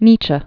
(nēchə, -chē), Friedrich Wilhelm 1844-1900.